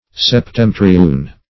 Septemtrioun \Sep*tem"tri*oun\, n.